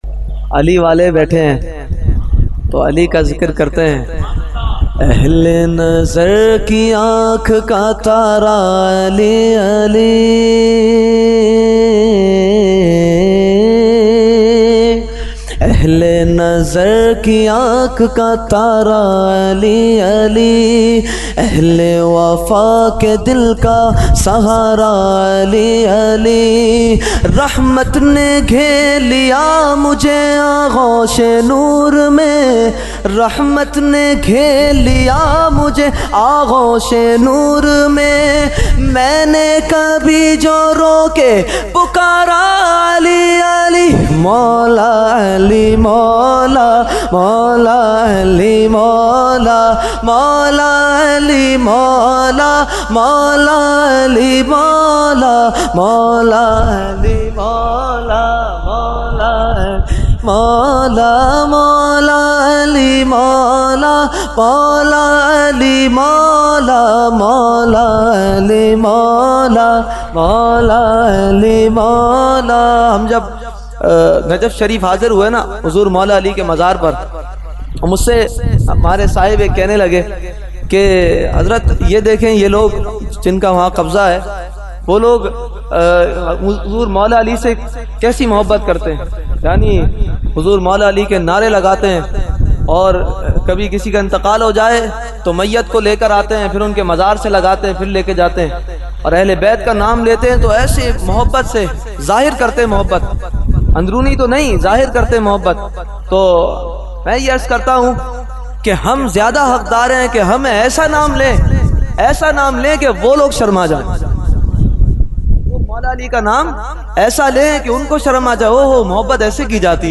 Category : Manqabat | Language : UrduEvent : Khatmul Quran 2020